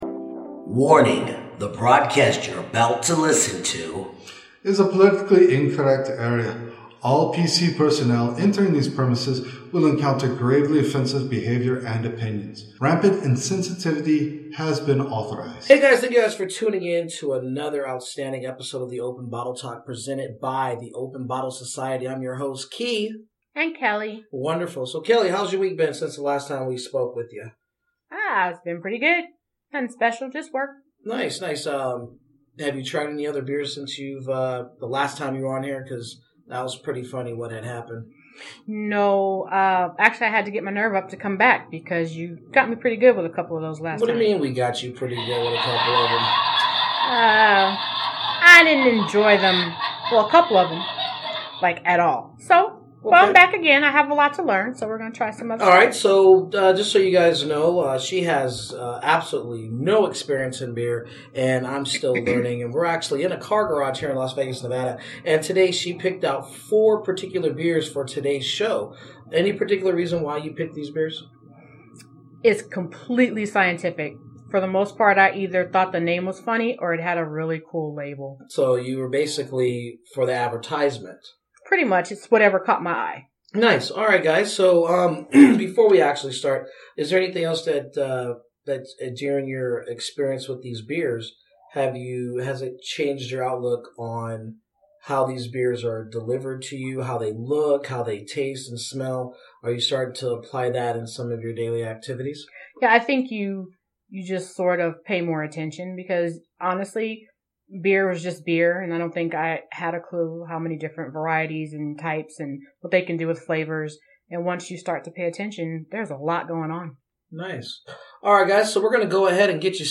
Styles: Beer Talk, Beer News, Beer, Talk Show